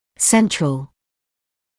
[‘sentrəl][‘сэнтрэл]центральный